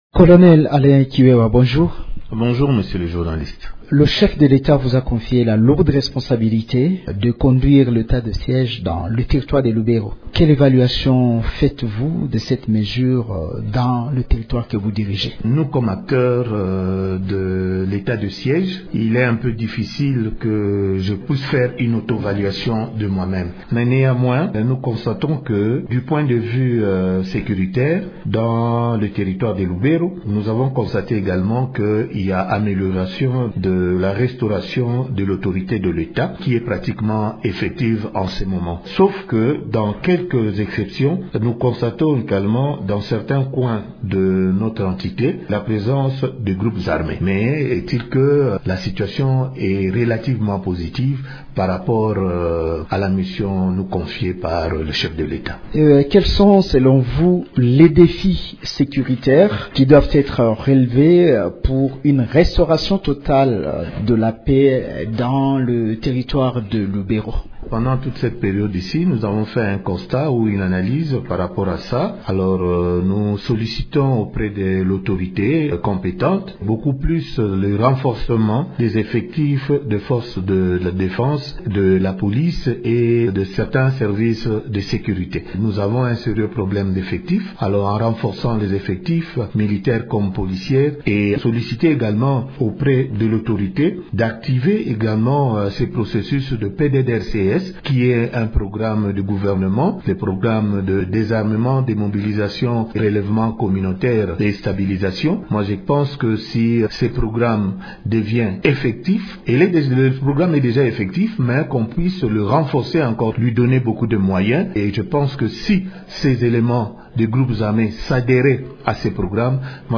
Le colonel Alain Kiwewa, administrateur de territoire de Lubero, parle de la stratégie de lutte contre l’insécurité dans certaines entités du territoire de Lubero sous occupation des groupes armés. Il estime que cet objectif sera atteint avec le renforcement des effectifs des Forces de défense et de sécurité et l’activation du Programme de désarmement, démobilisation et relèvement communautaire et stabilisation (PDDRC-S).
Il aborde aussi le point lié aux mesures prises pour empêcher les miliciens de tracasser la population, dans cet entretien